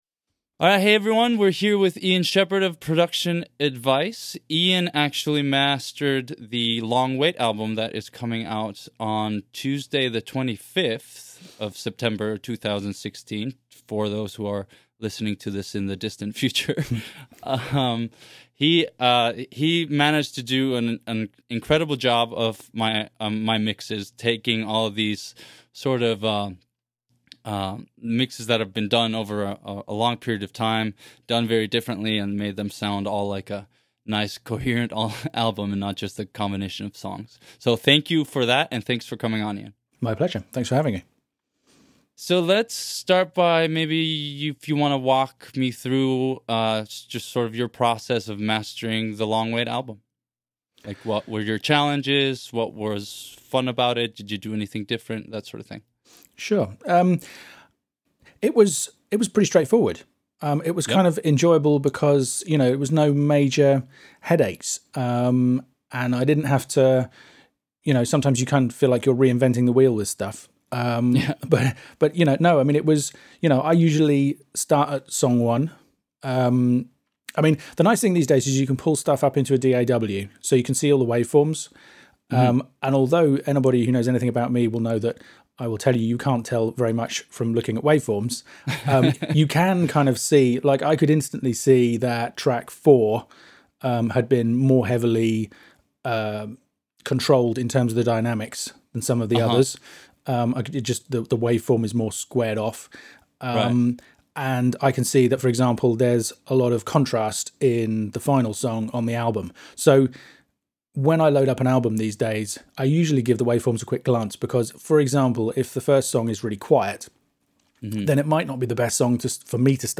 An In-Depth Interview